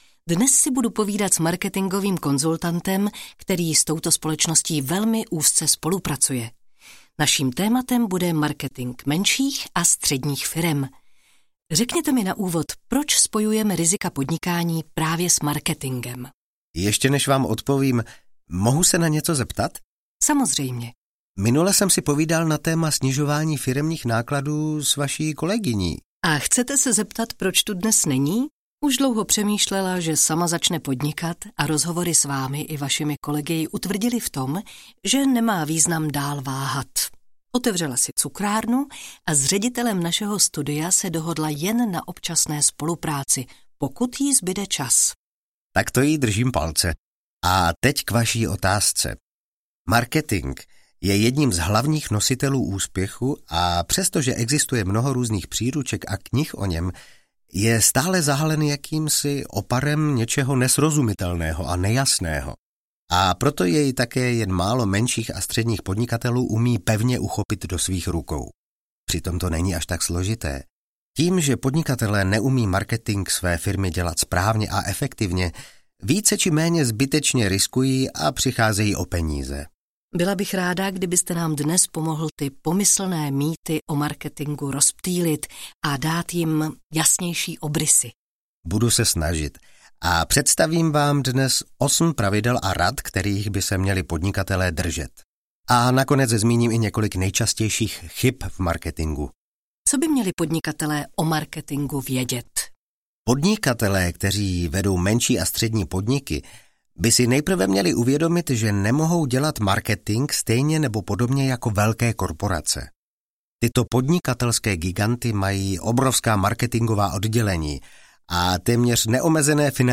Ukázka z knihy
marketing-mensich-a-strednich-firem-audiokniha